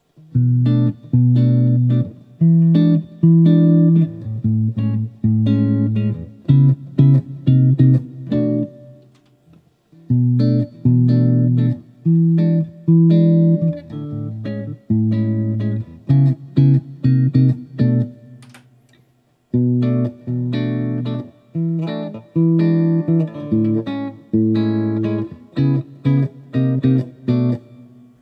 Finally, I did the unthinkable and recorded myself abusing both guitars with my wretched finger-style playing.
Again, I detect a bit more tightness in the bottom end of the 1986 X-500 with its sound block.